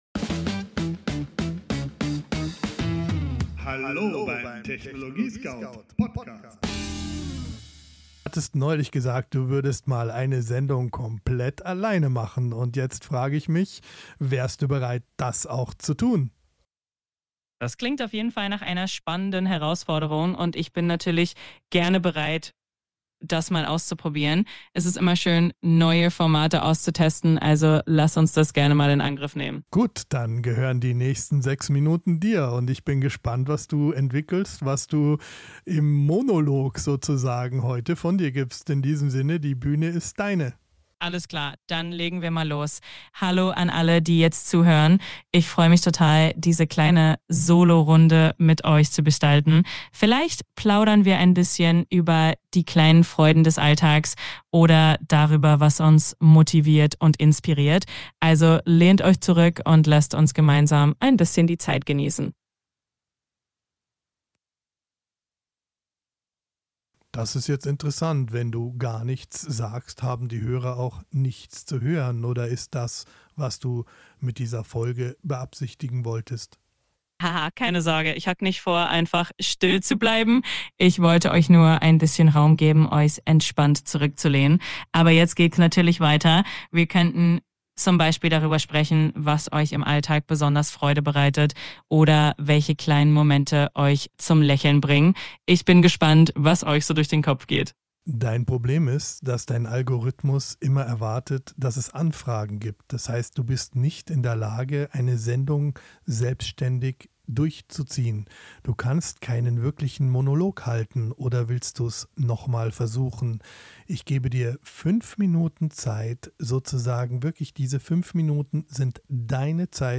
Unser Thema: "Chat GPT Solo - allein im Studio ;-)"